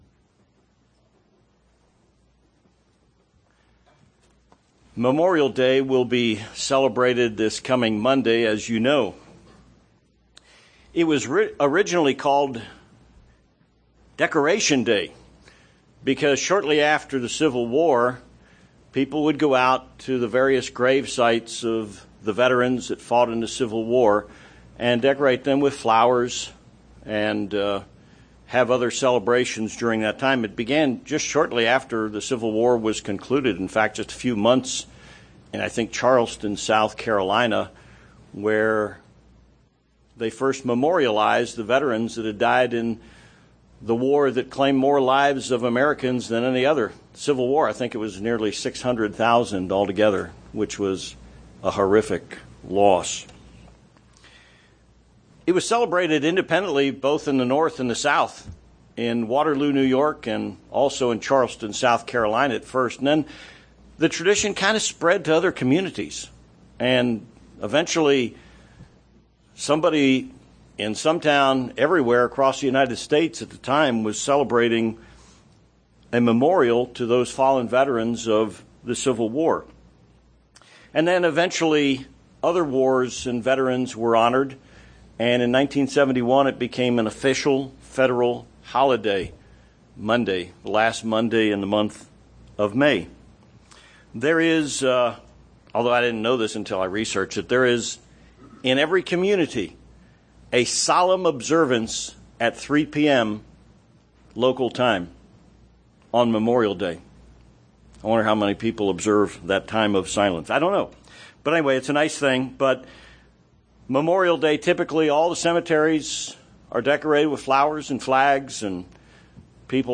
The Bible also honors its fallen heroes. This sermon examines the examples of four individuals who sacrificed in service to their nation.